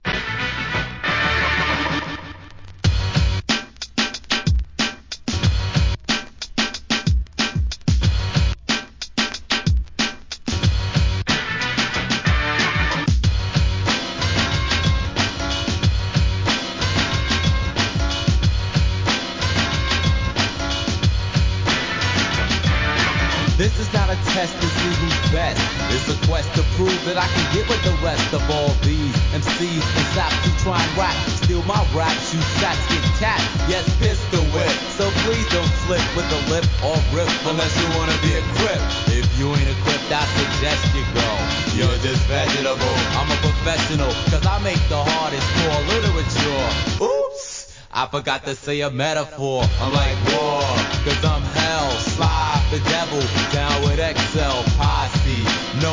1989年、双子ラッパーによるアッパーMIDLE SCHOOL HIP HOP!!!